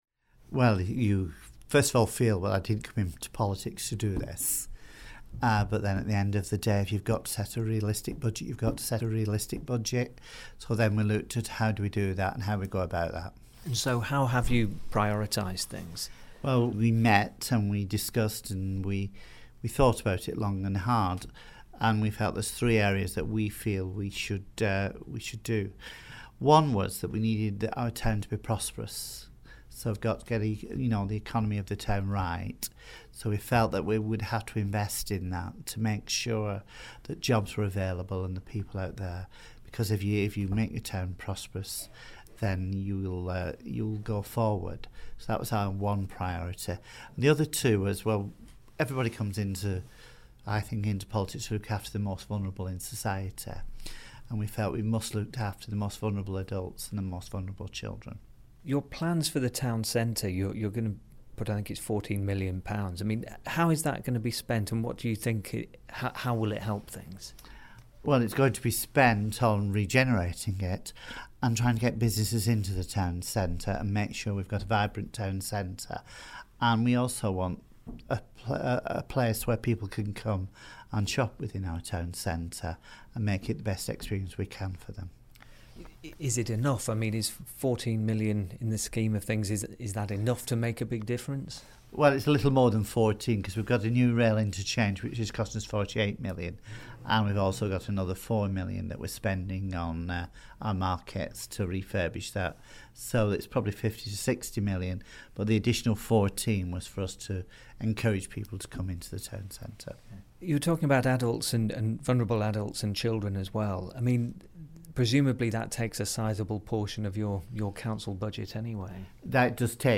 Hear from leader of Bolton Council, Cliff Morris on how the council is preparing to make more multi-million pound cuts.